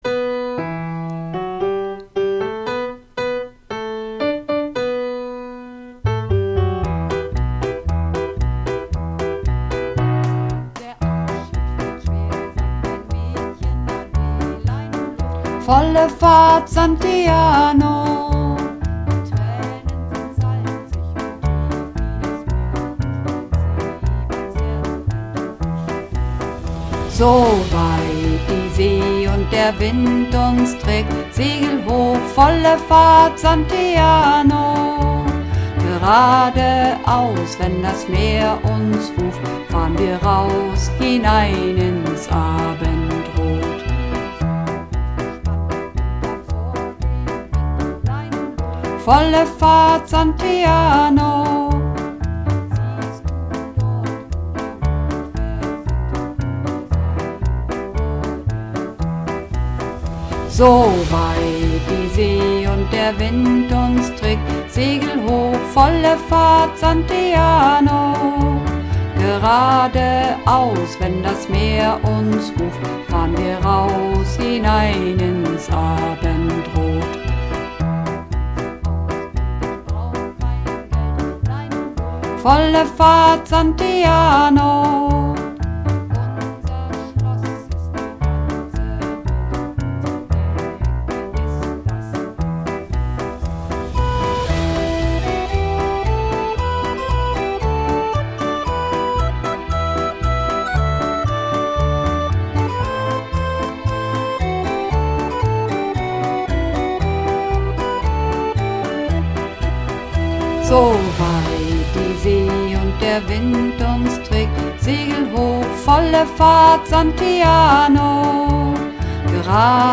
Übungsaufnahmen - Santiano
Runterladen (Mit rechter Maustaste anklicken, Menübefehl auswählen)   Santiano (Bass)